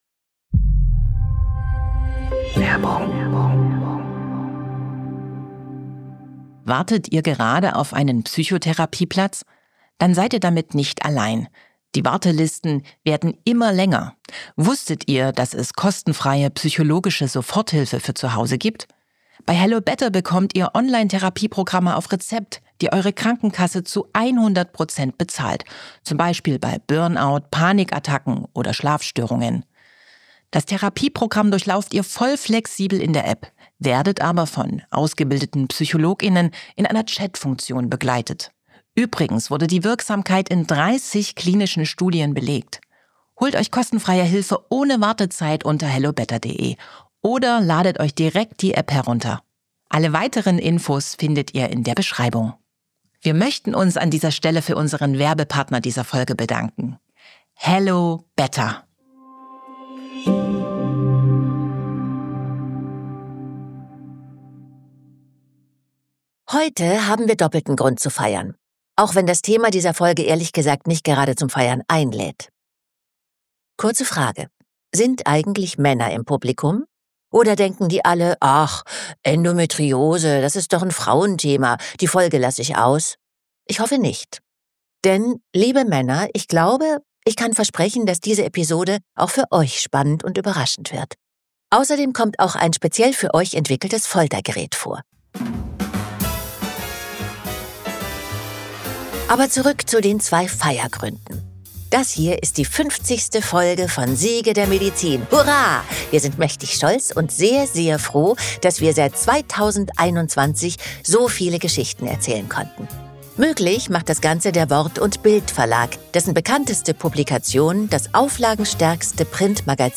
Andrea Sawatzki spricht über randalierende Gebärmütter, über aufgepumpte Hunde und einen „Sexfilm“ aus den Disneystudios. Es geht um Diagnoseverzögerungen und „Hab dich nicht so“-Sprüche und wir machen Menschen- bzw. Männerversuche mit einem Periodenschmerzsimulator.
Dieser Podcast handelt vom Kampf gegen Seuchen und Volkskrankheiten, von den größten medizinischen Erfolgen und den Persönlichkeiten, die sie vorantrieben – spannend erzählt von Schauspielerin Andrea Sawatzki.